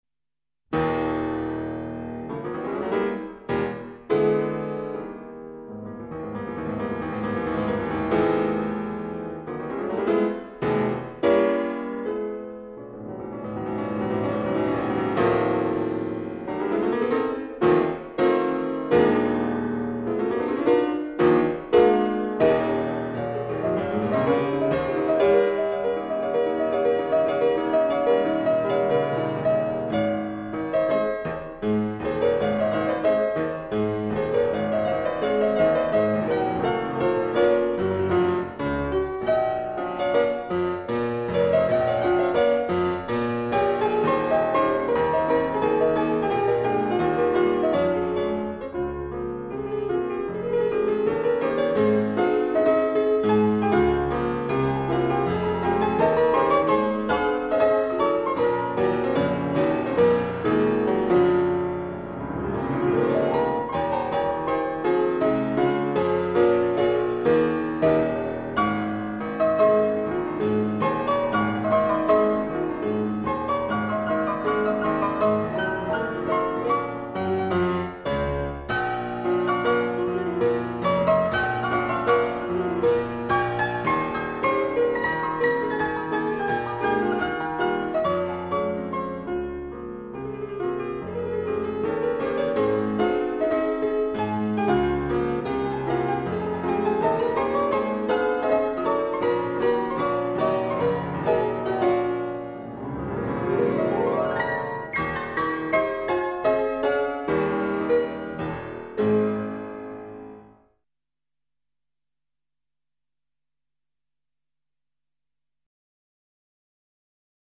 Piano.mp3